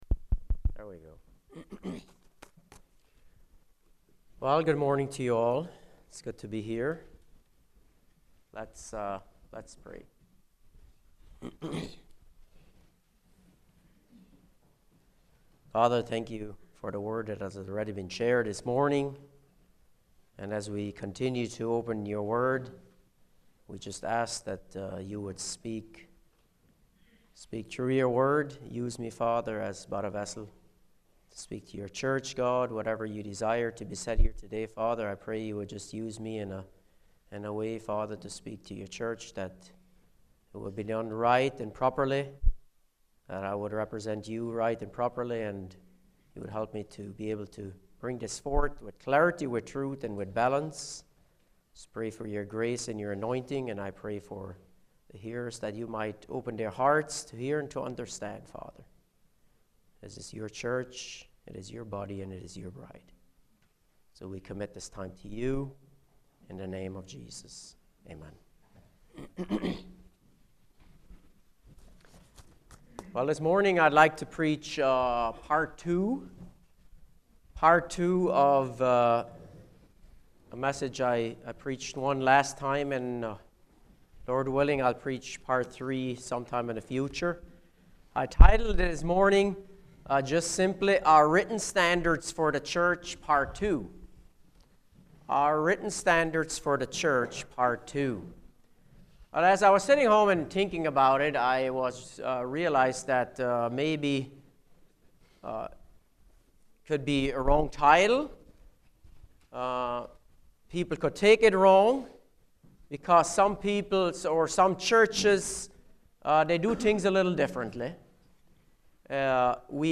Service Type: Sunday Morning Speaker